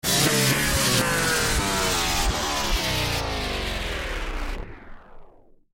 Звук ускорения для видеомонтажа